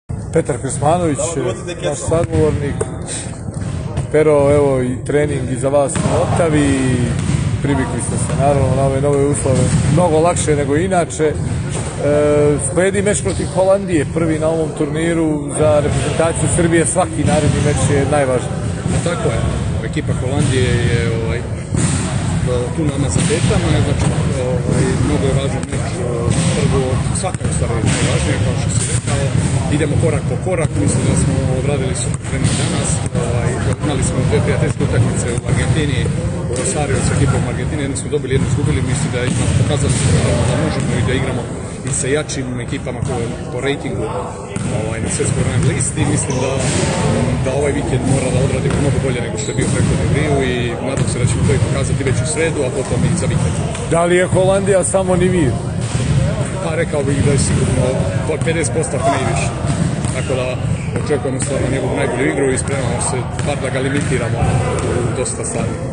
Izjava Petra Krsmanovića